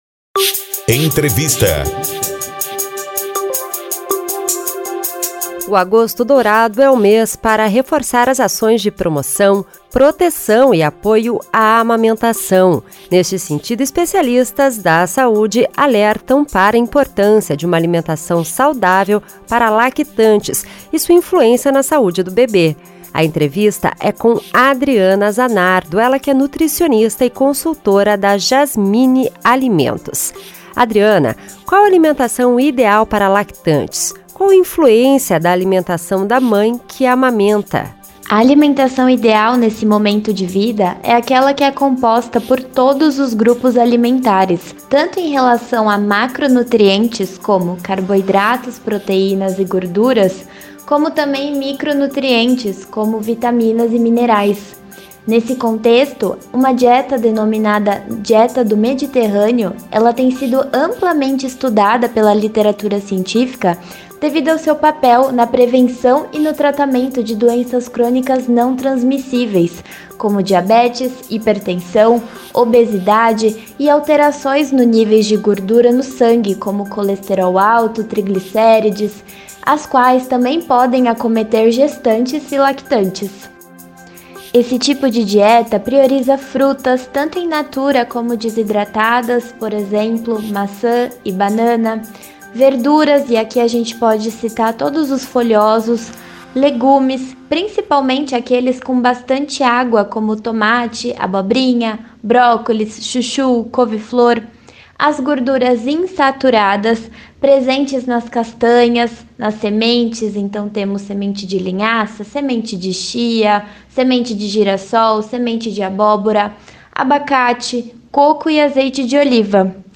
Neste sentido, especialistas da saúde alertam para a importância de uma alimentação saudável para lactantes e sua influência na saúde do bebê. A entrevista